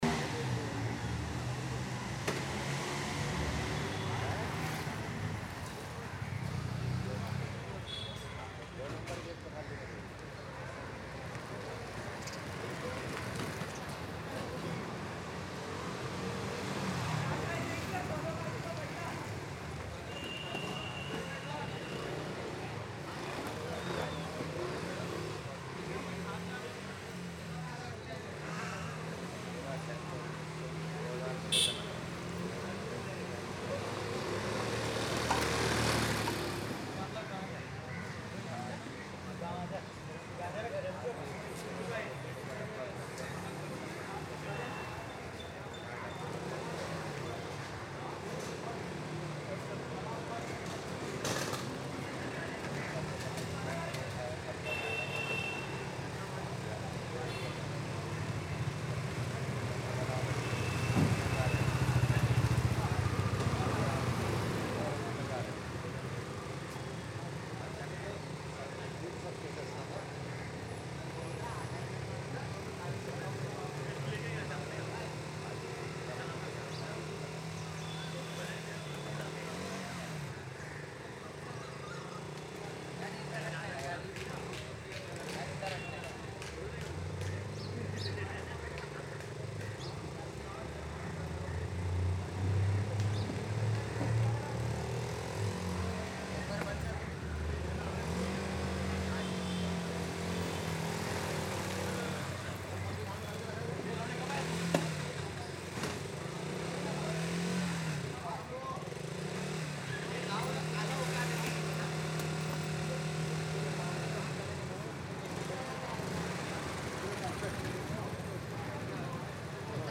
Busy Public -AMB024
Background crowd texture for realism
Continuous outdoor noise for natural atmosphere
This ambience captures the natural sounds of a crowded public area, filled with a blend of constant people chatter, footsteps, distant vendors calling out, soft honking, passing bikes, and the general hustle-bustle of a busy outdoor space.
The soundscape creates an energetic, realistic city environment with layered human voices—some near, some distant—adding depth and authenticity. Subtle traffic noise, bus movement, rustling, and street activity blend smoothly, making the ambience atmosphere-rich but not overly loud.
Public Crowd Ambience
Wide Stereo Field
Busy, Urban, Energetic
Outdoor / Human Activity
Twentyfour-Busy-Public.mp3